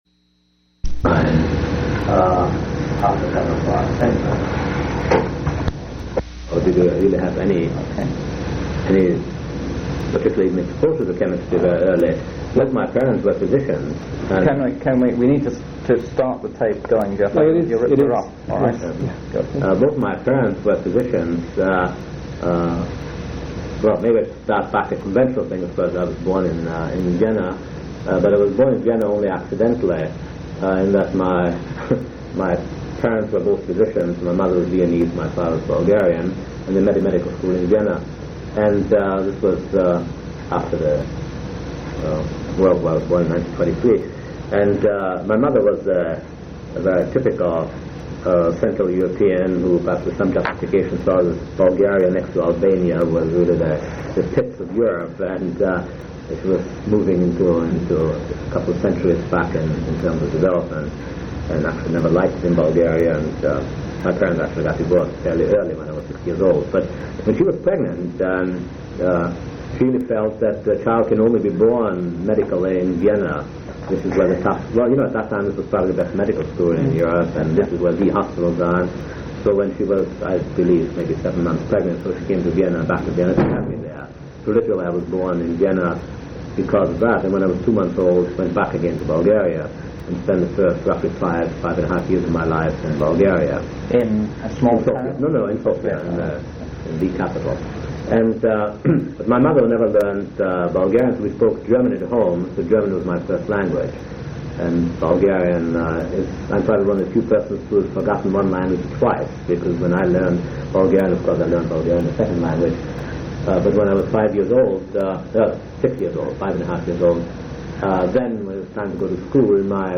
Oral history interview with Carl Djerassi